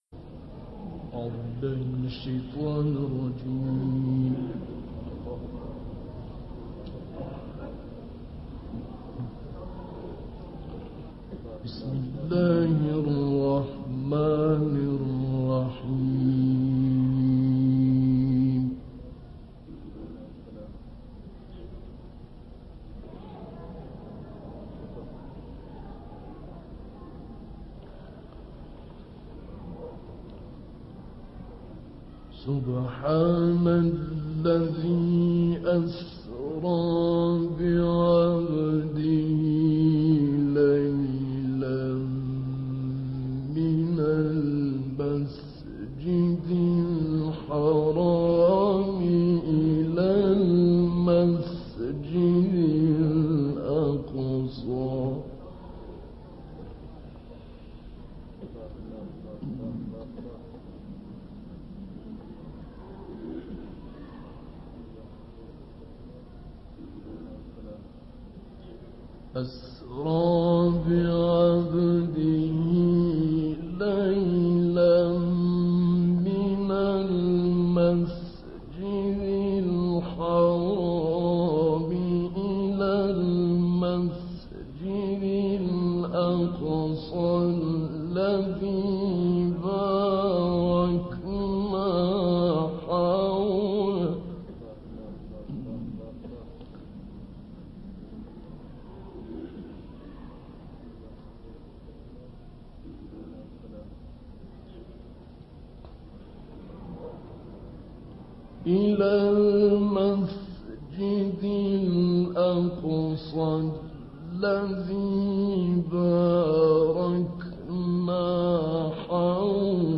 صوت/ تلاوت «شعیشع» از سوره مبارکه اسراء
گروه چندرسانه‌ای ــ تلاوت آیات ۱ تا ۲۵ سوره مبارکه اسراء را با صدای ابوالعینین شعیشع، قاری بنام مصری می‌شنوید.